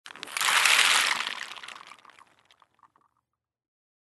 Высыпали горсть орехов в глубокую миску